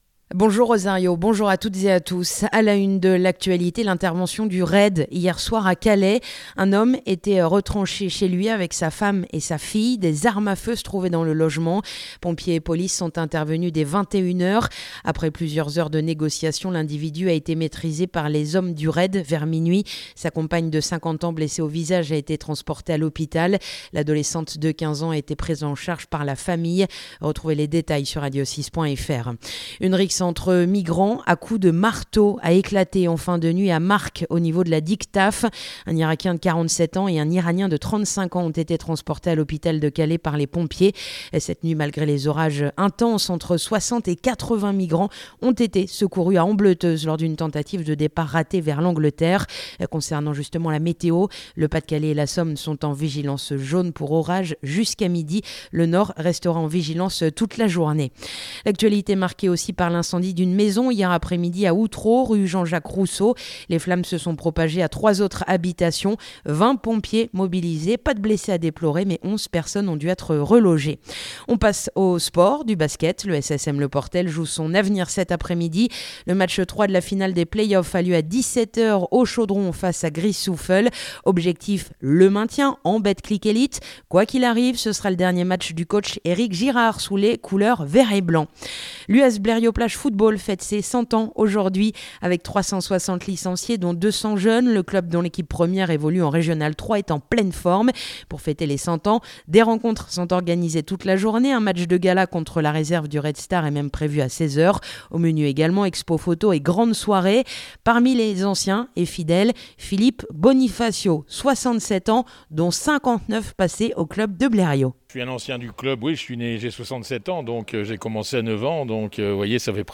Le journal du samedi 14 juin